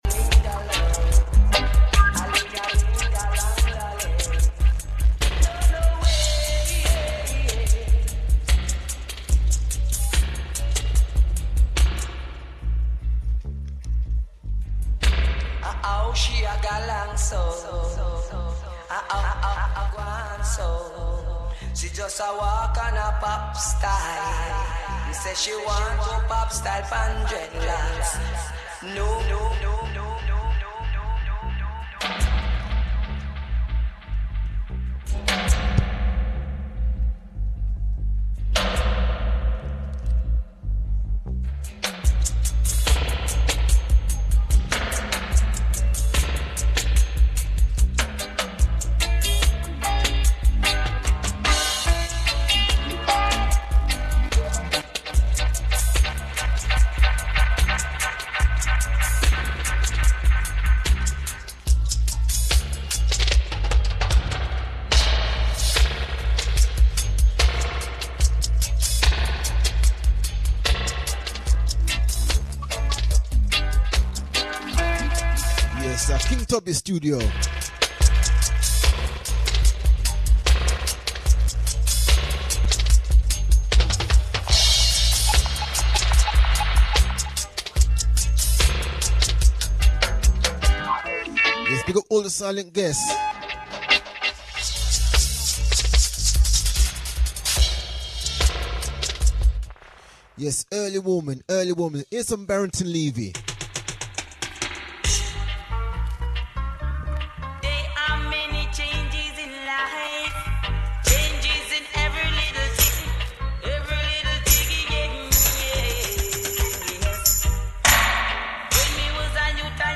strictly 70s & 80s Roots music